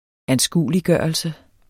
Udtale [ -ˌgɶˀʌlsə ]